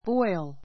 bɔ́il